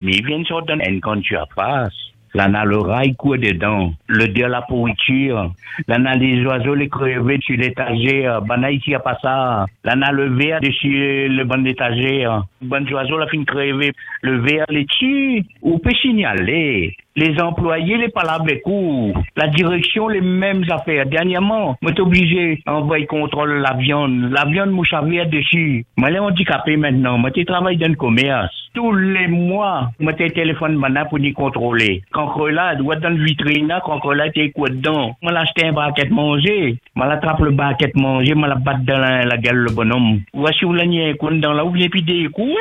Un auditeur raconte une expérience qu’il qualifie d’horrible dans une grande surface de l’île.